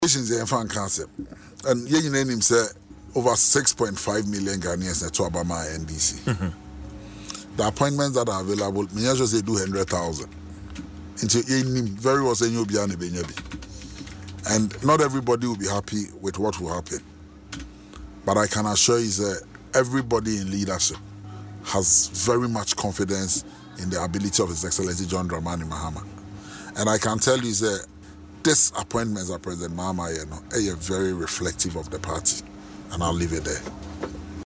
Speaking on Adom FM’s Dwaso Nsem morning show, Mr. Opare-Addo acknowledged that not everyone would be satisfied with the appointments but assured party members that the selections were made in the best interest of the party and the country.